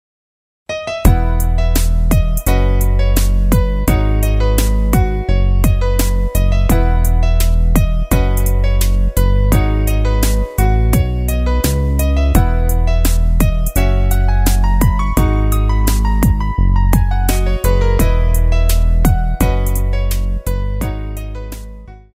원곡의 보컬 목소리를 MR에 약하게 넣어서 제작한 MR이며